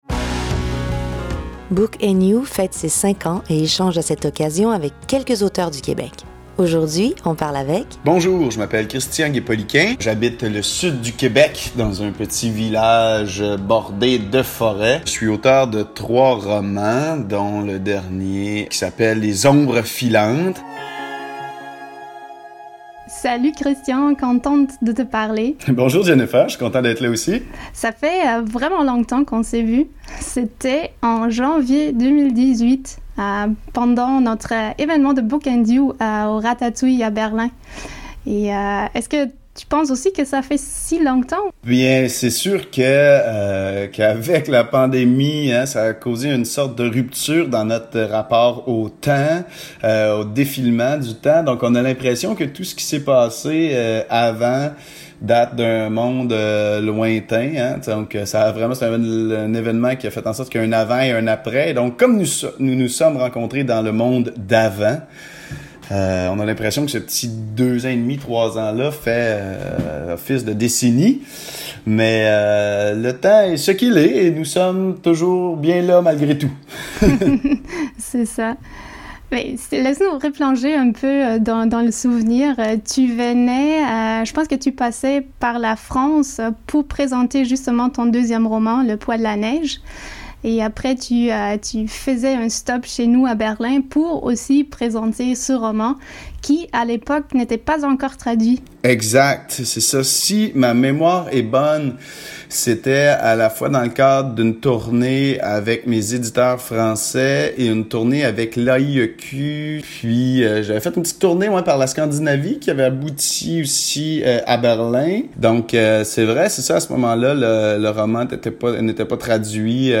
animation
invité
voix